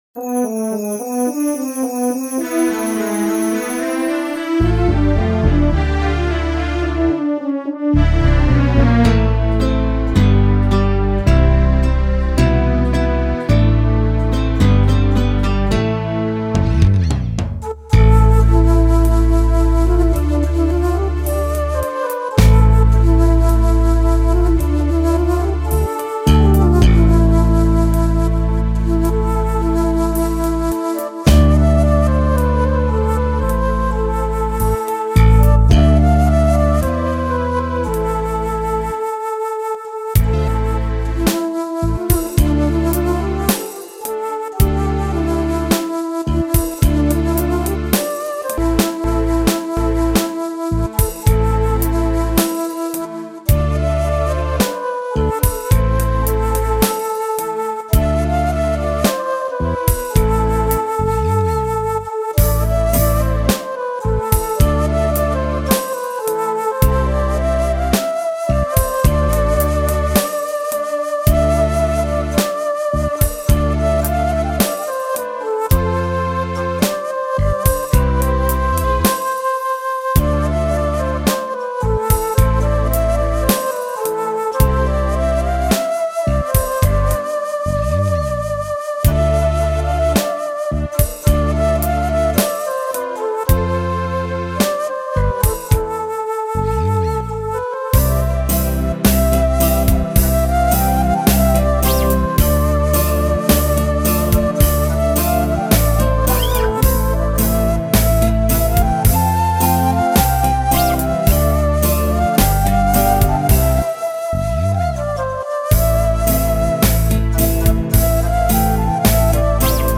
מקצבים שבניתי על קורג pa700
ההקלטה לא היתה חלקה, וזה טיפה ערוך (חיצוני), אשמח לביקורת ny dance 01.mp3 ny-s dance 06.MP3